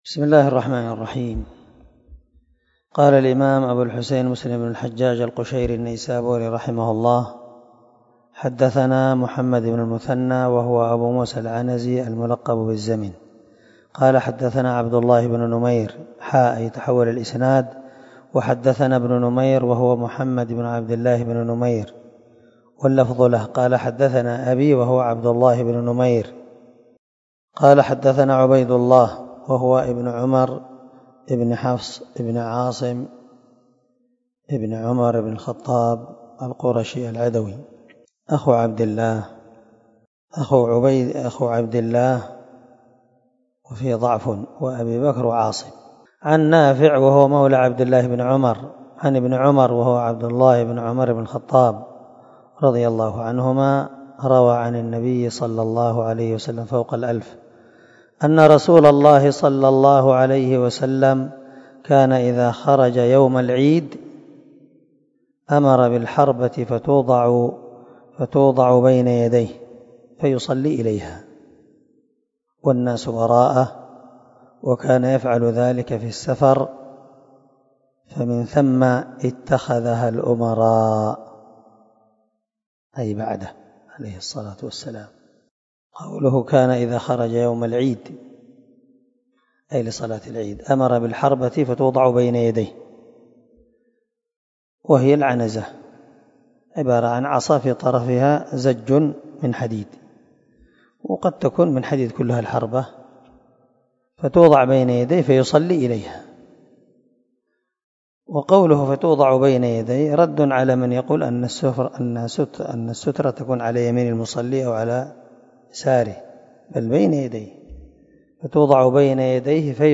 322الدرس 66 من شرح كتاب الصلاة حديث رقم ( 501 - 503 ) من صحيح مسلم